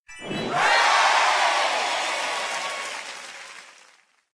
winner.ogg